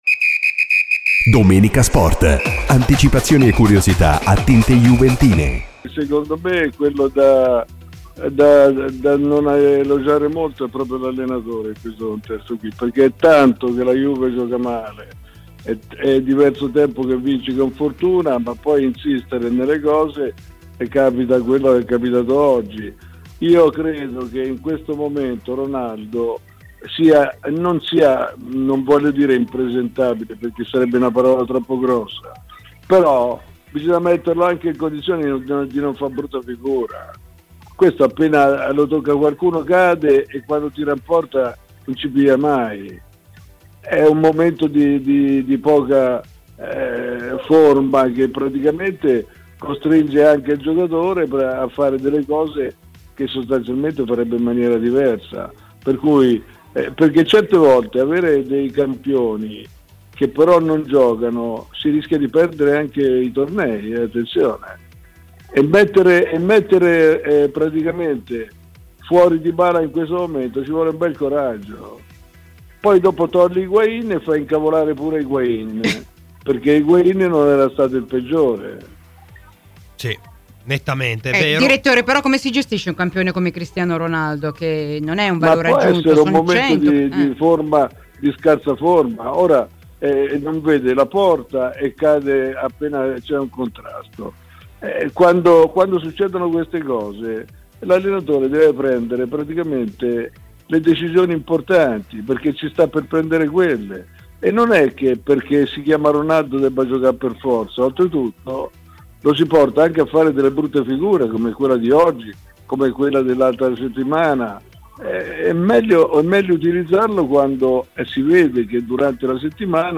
Luciano Moggi al termine della partita Juventus-Sassuolo, ai microfoni di "Colpo di Tacco" © registrazione di Radio Bianconera